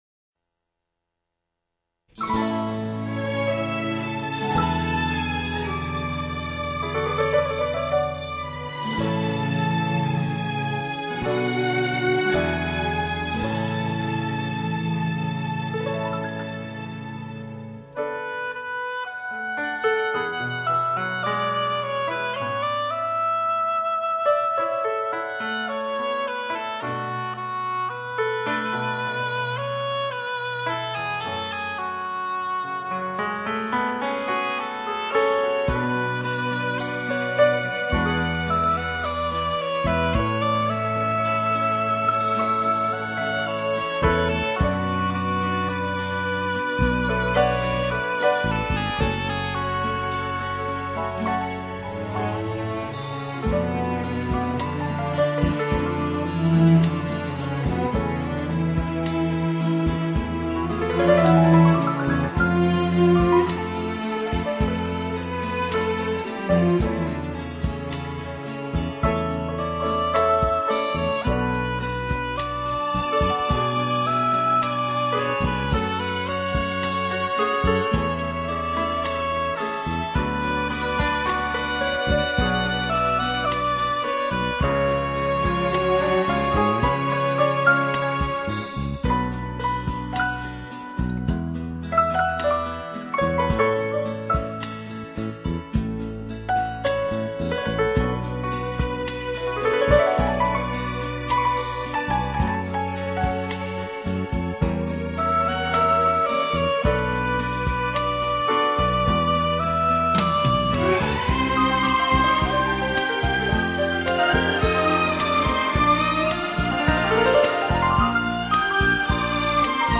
第一次听《波罗维茨人之舞》是在一个冬日的午后，那悠扬宁静的旋律传来，是如此的温暖如水，而在接近灵魂的一刹那，却又似一剑穿心，冰冷彻骨。